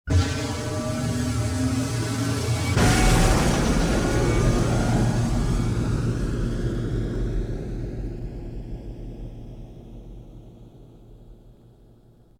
AsteroidLaunch.wav